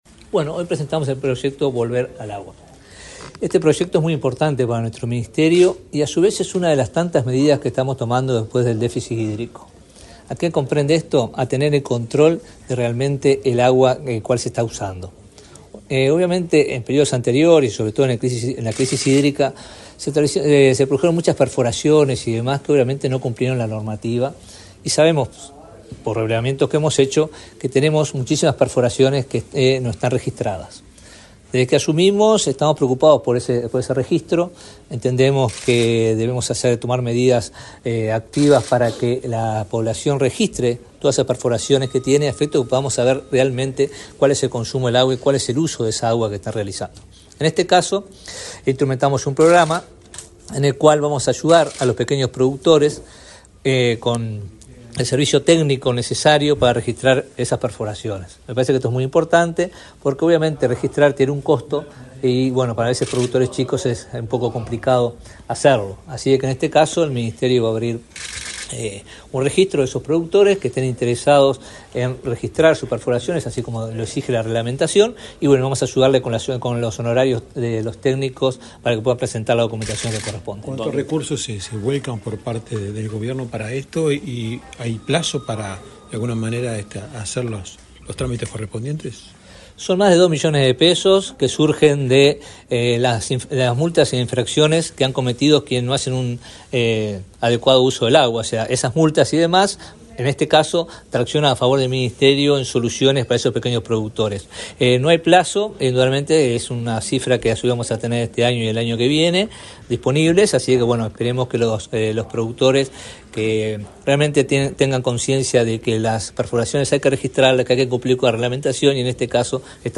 Declaraciones del ministro de Ambiente, Robert Bouvier
Este lunes 20 en Montevideo, el ministro de Ambiente, Robert Bouvier, dialogó con la prensa, antes de participar en la presentación del programa